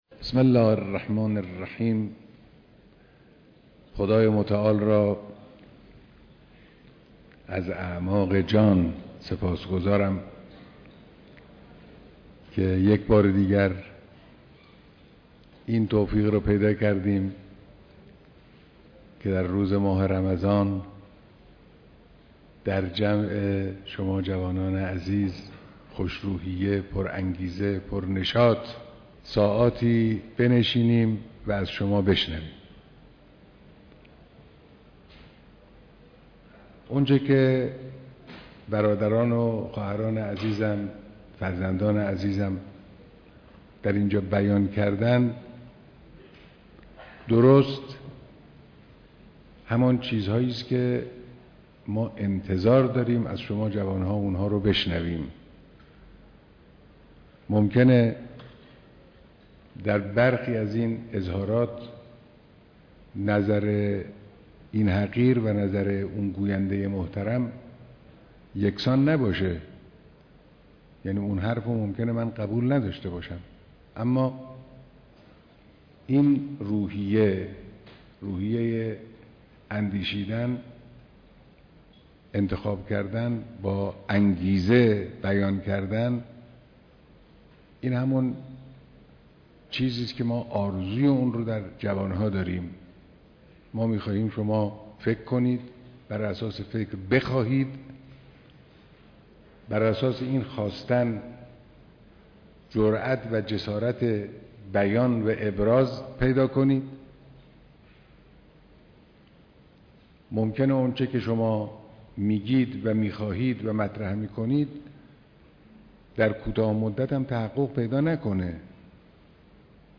بیانات در دیدار جمعی از دانشجویان